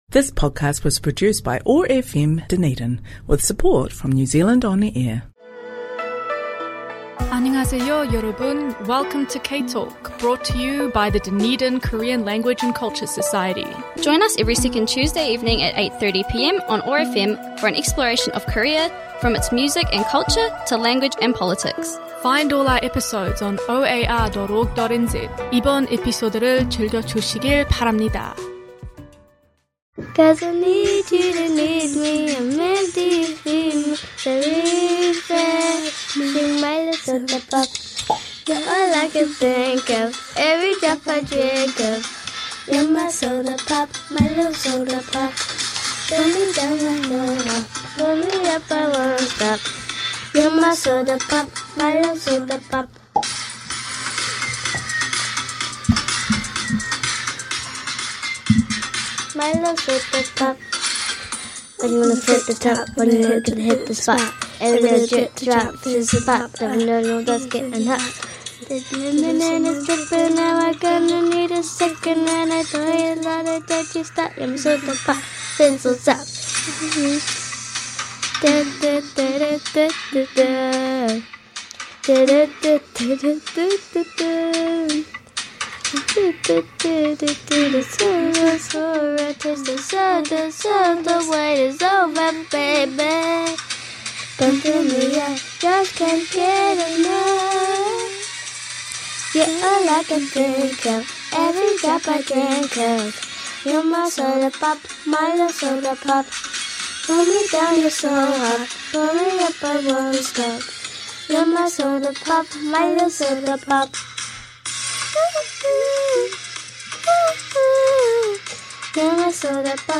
Join the K-Talk team and special guests for a celebration of Korean culture and music.